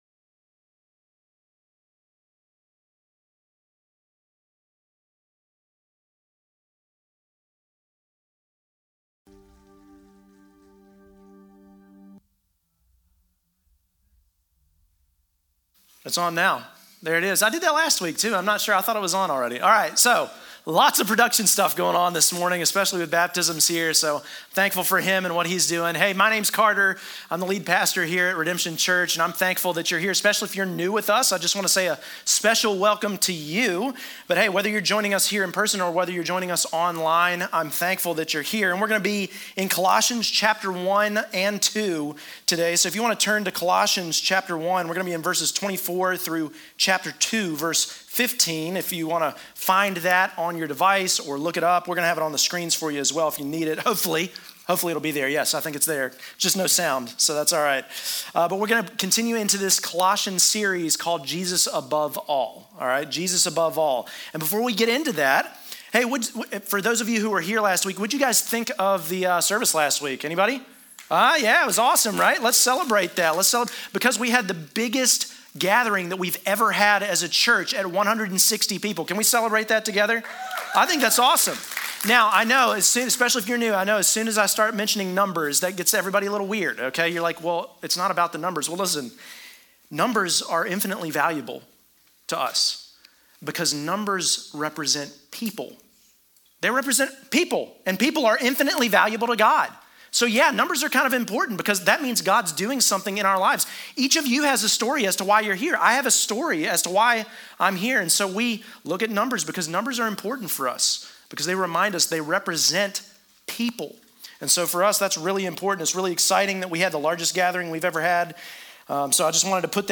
Sermon-Audio-4-16-23.mp3